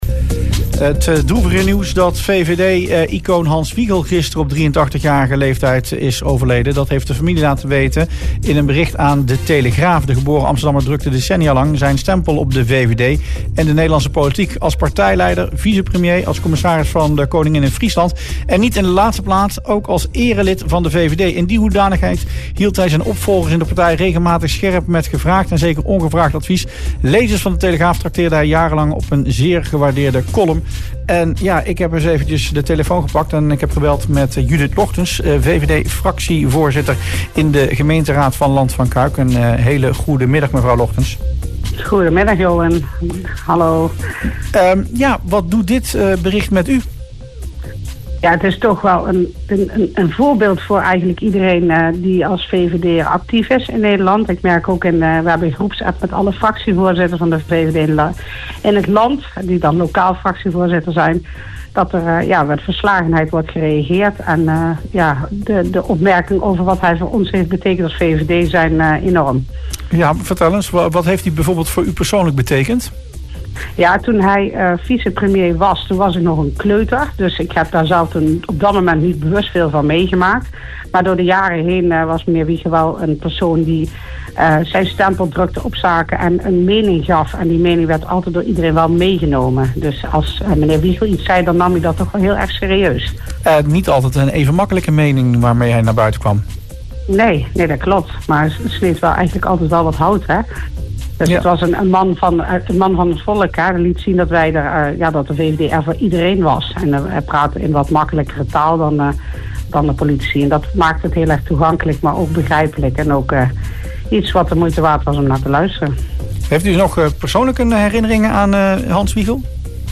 LAND VAN CUIJK – VVD-raadslid Arthur Baudet heeft in het radioprogramma Rustplaats Lokkant gereageerd op het overlijden van oud-politicus Hans Wiegel. Baudet noemt Wiegel een toonbeeld van het liberalisme en een invloedrijke stem binnen de Nederlandse politiek.